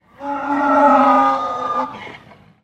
Звуки мула
Звук лошадиного протеста в конюшне